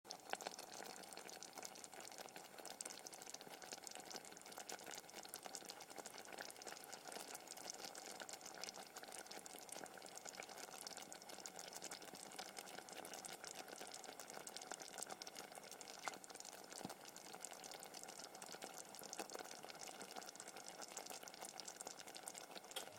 浓稠的液体泡沫2
描述：冒泡，浓稠的液体
标签： 气泡 气泡 鼓泡 液体 药水 化工
声道立体声